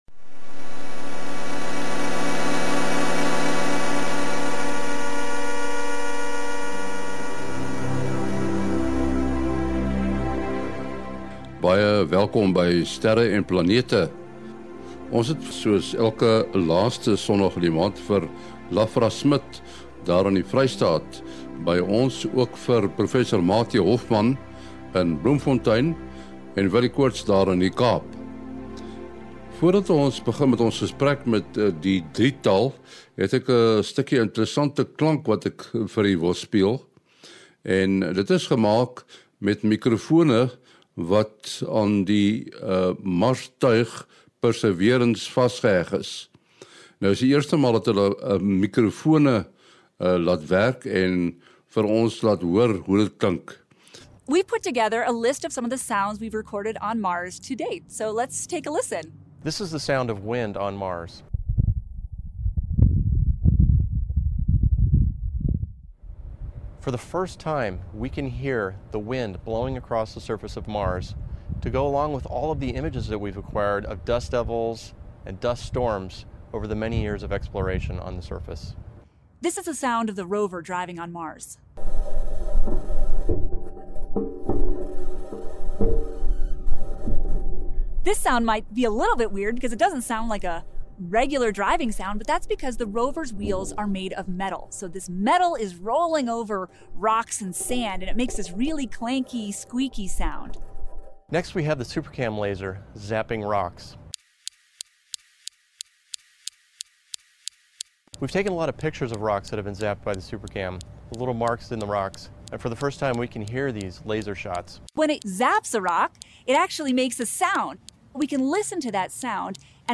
Het jy al gehoor hoe dit klink wanneer die metaalwiele van Perseverance oor die klippe van Mars ry? Ons speel ‘n paar opnames wat deur die aanboord-mikrofone van die Marstuig opgeneem is.